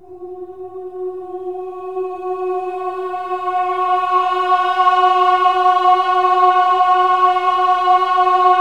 OH-AH  F#4-L.wav